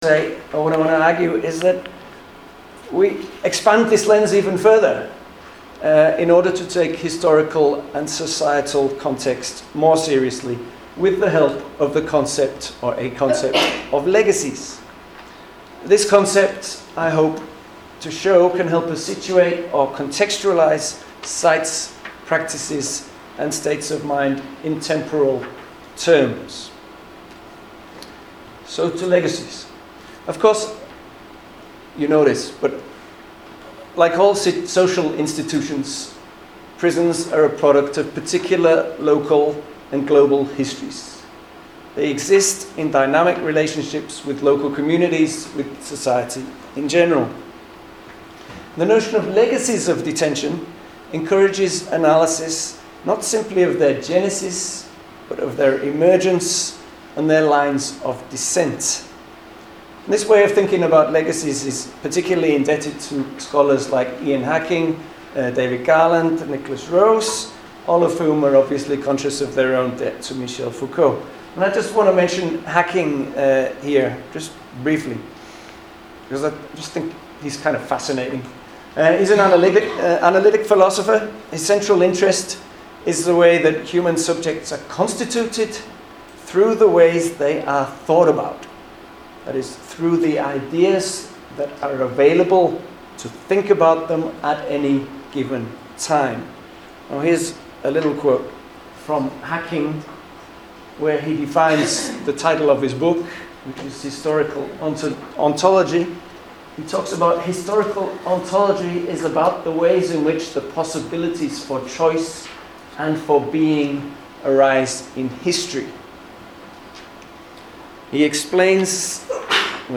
Prison and Politics in Myanmar” (A partial recording, due to technical difficulties) Slides